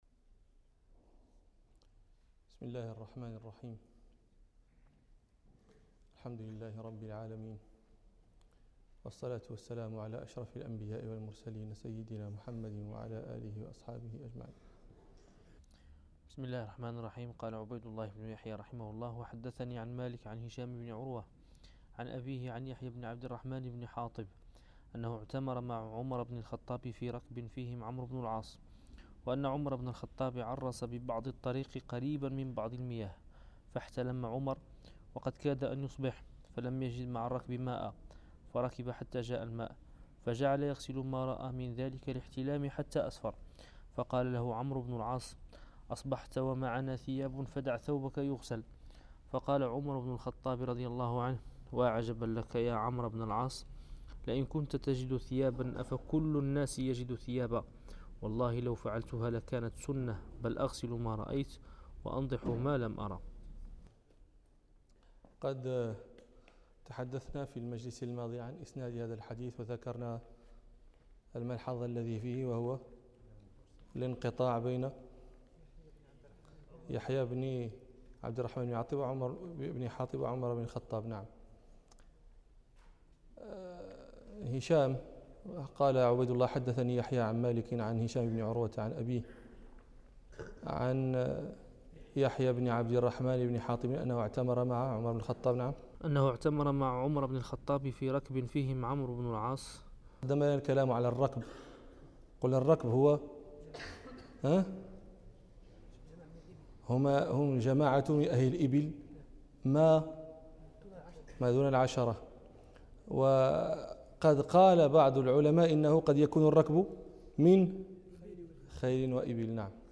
الدرس الرابع والأربعون من دروس كرسي الإمام مالك